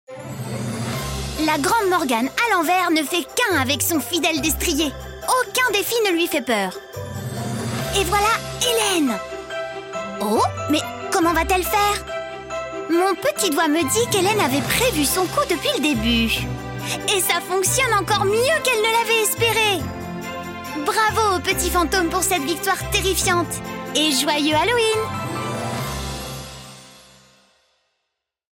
Jeune, Enjouée, Commerciale, Naturelle, Polyvalente